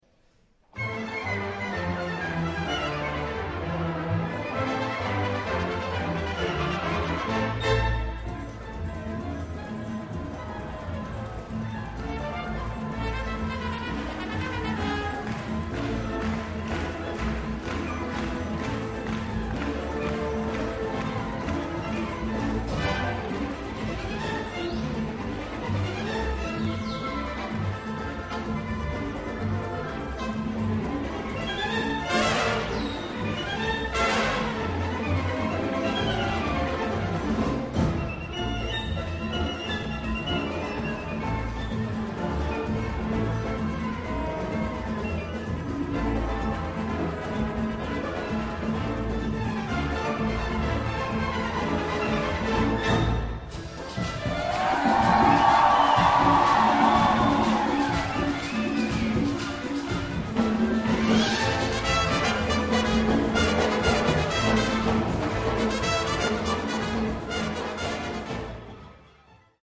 actuación
Orquesta Sinfónica Juan José Landaeta, dirigida por el maestro Christian Vásquez